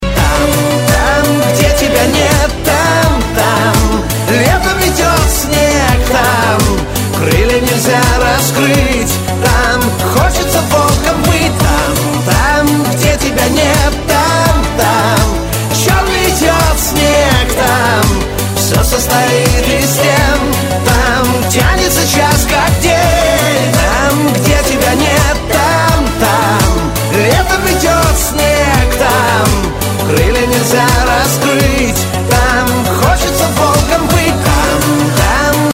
Категория: Шансон рингтоны